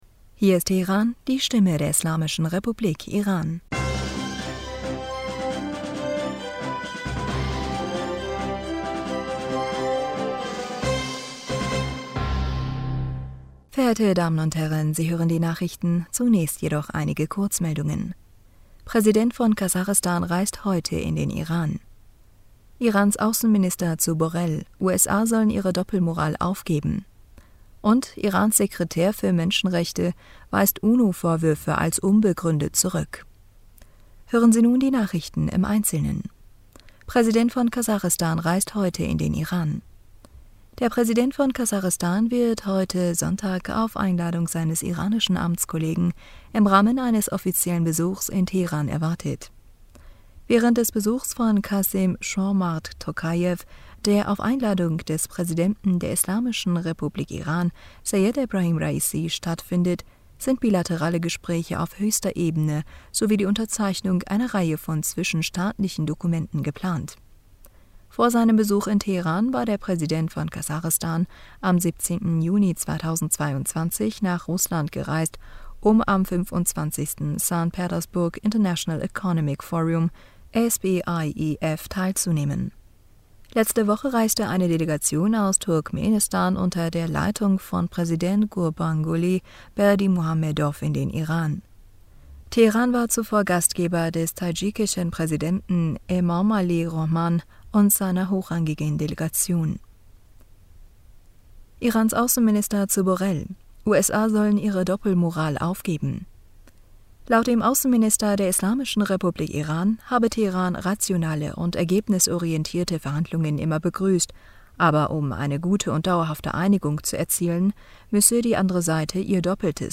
Nachrichten vom 19. Juni 2022